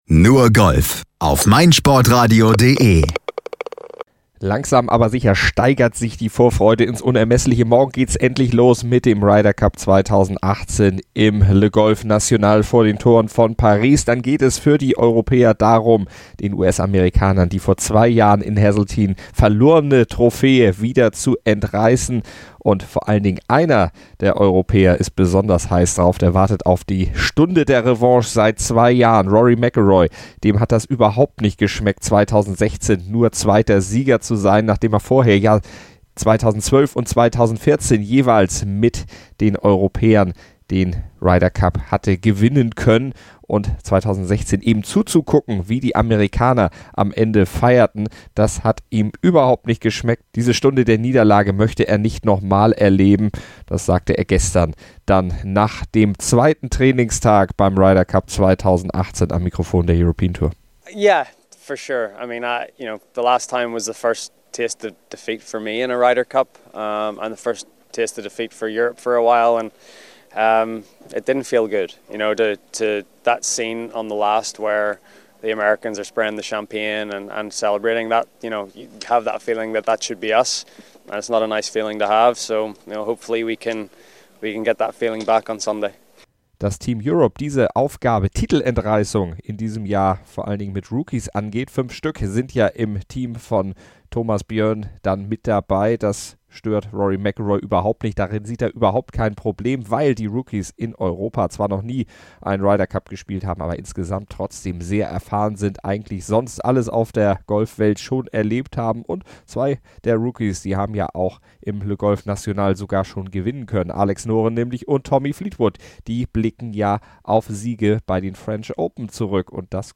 Im Interview erzählt Rory McIlroy über seine "Rachegelüste" und schätzt die Stärke der Rookies im europäischen Team ein. Dazu sprechen Thomas Björn und Jim Furyk über ihre Teams und letzten Aufgaben, bevor es dann morgen los geht.